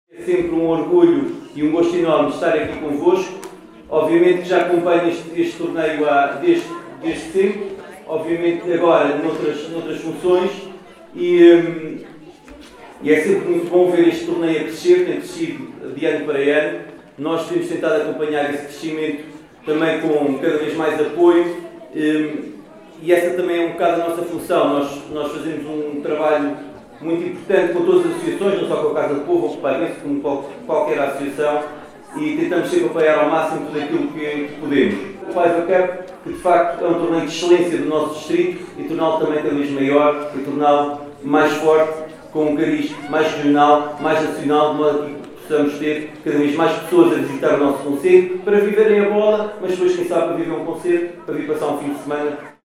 Na tarde deste sábado, 12 de abril, decorreu no Auditório Municipal de Vila Nova de Paiva, a apresentação oficial do 10º Torneio de Futebol Infantil – Paiva Cup 2025, que se vai realizar na próxima sexta-feira, 18 de abril, nos escalões de sub 9 e sub 10 e sábado, dia 19, nos escalões de sub 12 e sub 13.